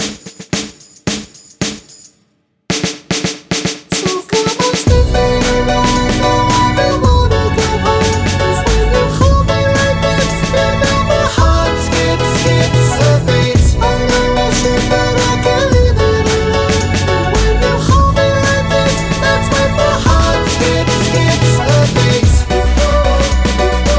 No Rapper Pop (2010s) 3:24 Buy £1.50